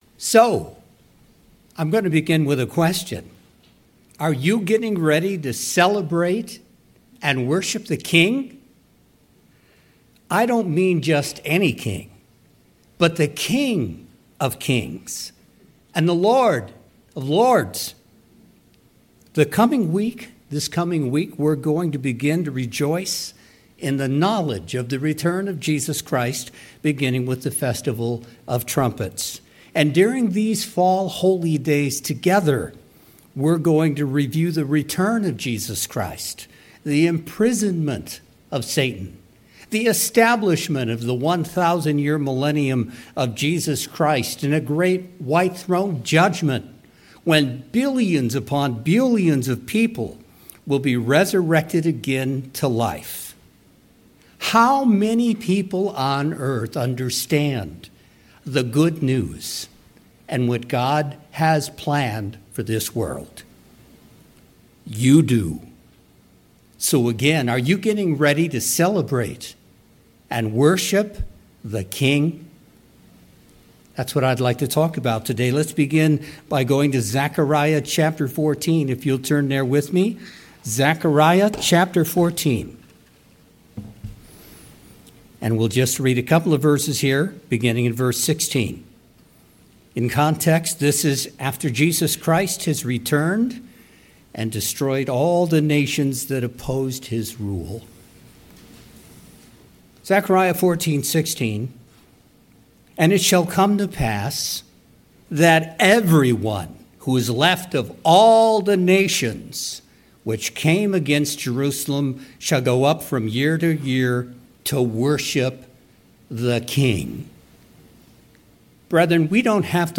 Few people today truly understand the good news of the coming Kingdom and God’s plan. In this sermon, we will explore the promised qualities of that Kingdom, identify who the King is and the power of His name, and reflect on the example of worship He gave while walking on earth.